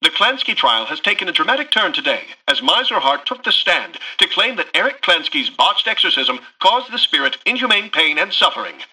[[Category:Newscaster voicelines]]
Newscaster_headline_43.mp3